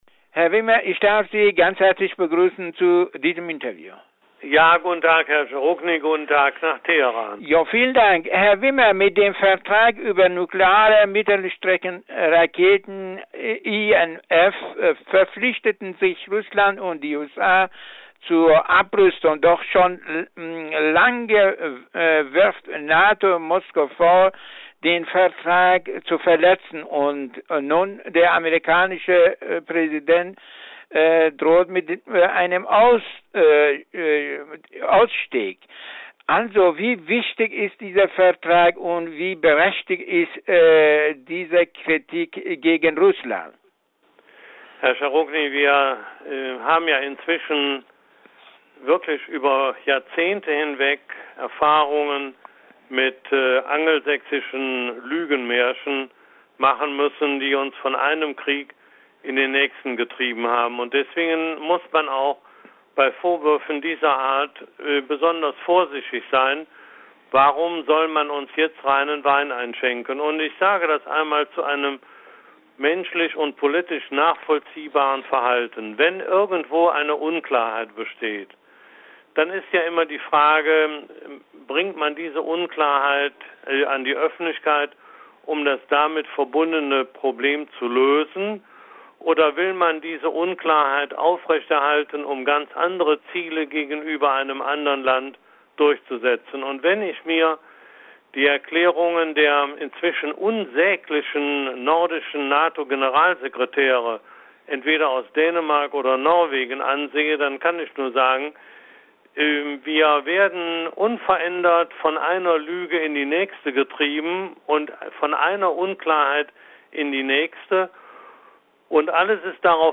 Interview mit Willy Wimmer